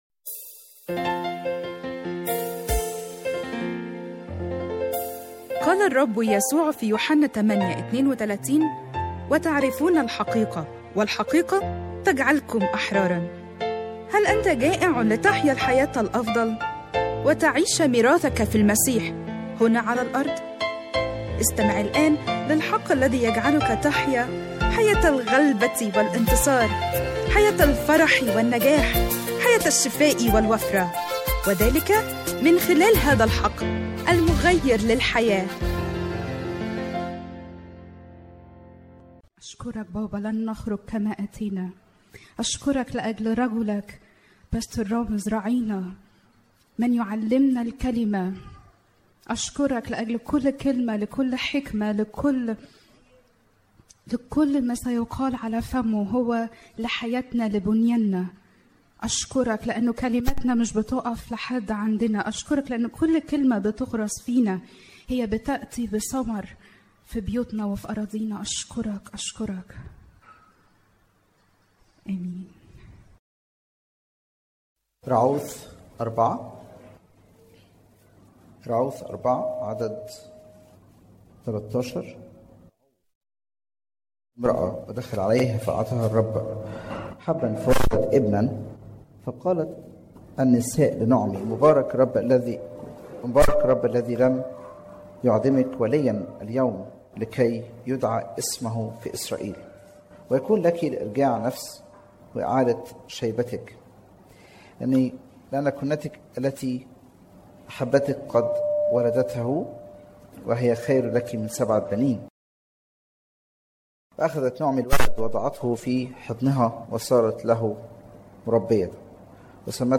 *تنويه: هذه السلسلة متاحة مسموعة في اكثر من عظة اما النص المكتوب فهو للسلسلة كاملة لسماع باقي أجزاء السلسلة اضغط هنا